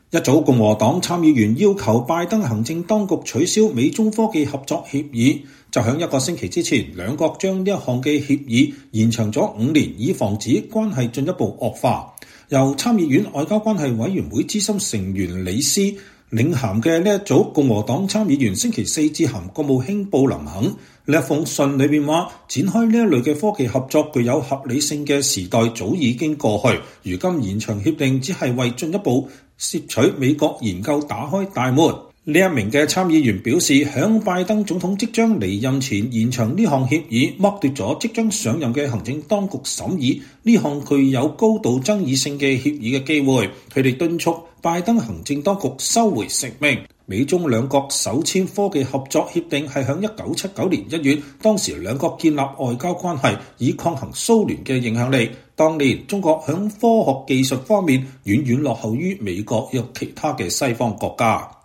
參議院外交委員會資深成員里施在外委會的聽證會發言。 (2023年10月18日)